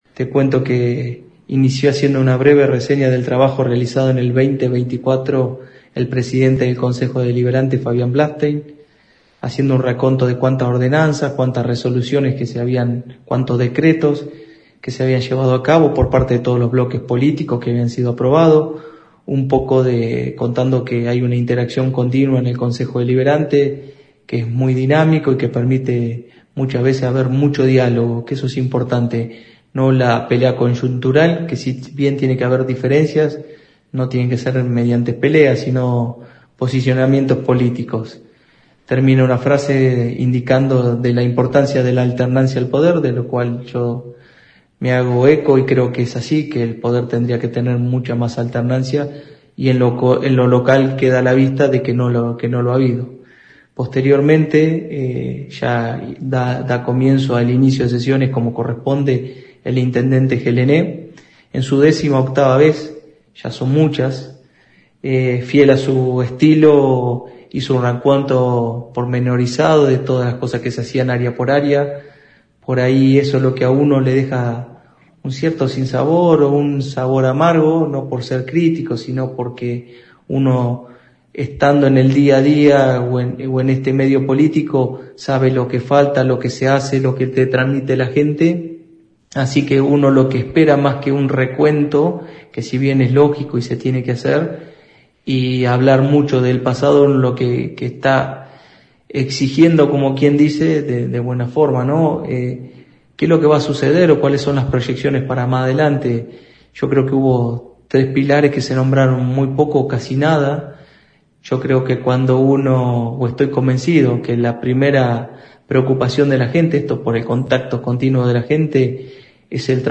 (incluye audios) Luego del tradicional mensaje del jefe comunal quien dejó inaugurado un nuevo período de sesiones ordinarias en el salón «Dr. Oscar Alende», la 91.5 habló con referentes de los tres bloques políticos que conforman el HCD local.
Concejal Federico Dorronsoro (Bloque Adelante Juntos):